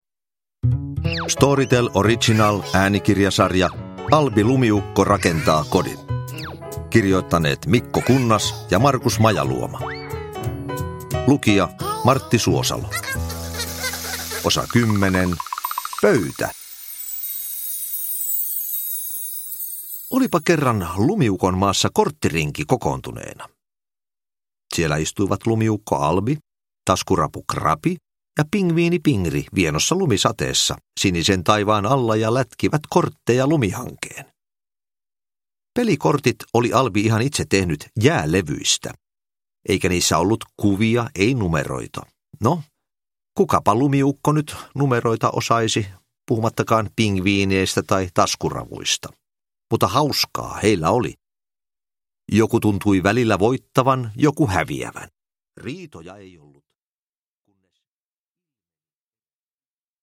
Albi rakentaa kodin: Pöytä – Ljudbok – Laddas ner
Uppläsare: Martti Suosalo